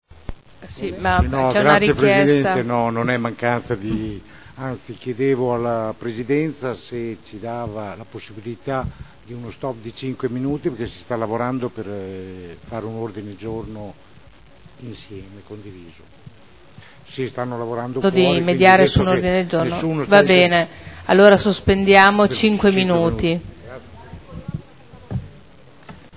Seduta del 11/04/2011. Richiesta di sospensione lavori